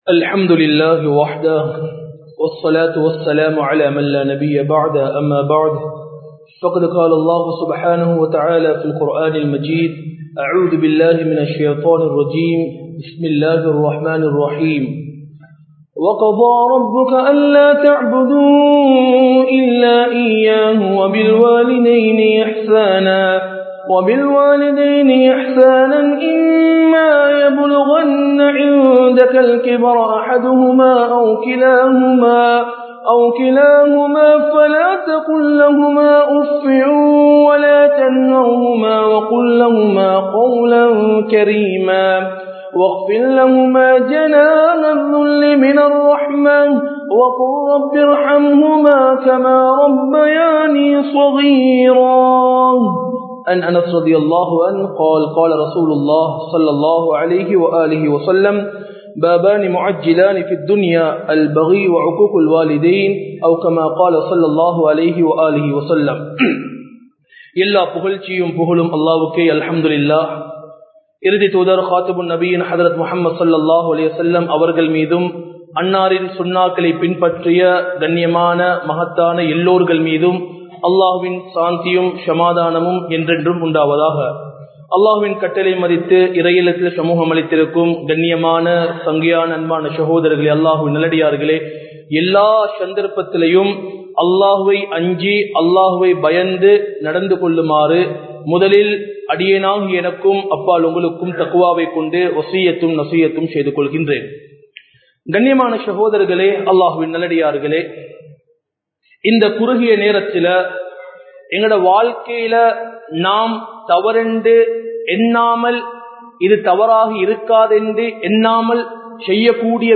Pettroarhalukku Noavinai Seithavarhalin Nilai (பெற்றோர்களுக்கு நோவினை செய்தவர்களின் நிலை) | Audio Bayans | All Ceylon Muslim Youth Community | Addalaichenai
Majma Ul Khairah Jumua Masjith (Nimal Road)